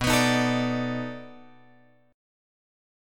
B 7th Sharp 9th